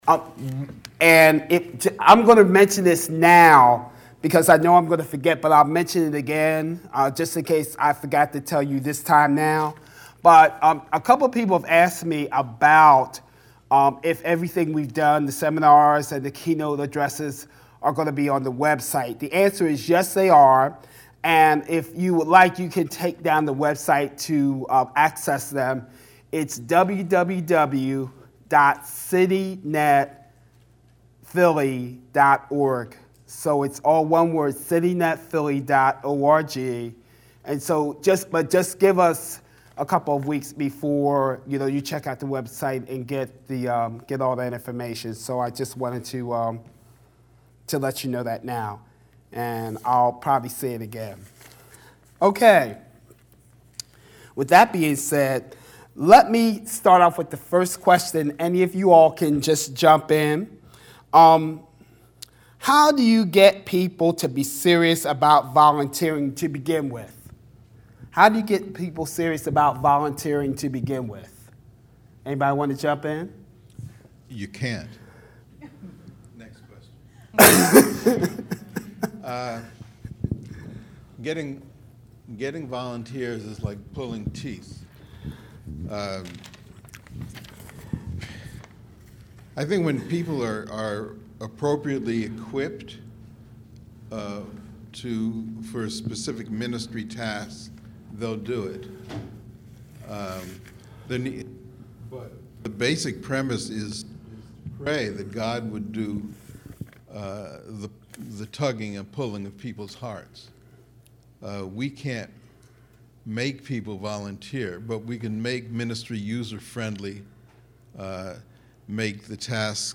2008 MercyNet Conference | Monergism
Panel Discussion with All Participants
PanelDiscussion.mp3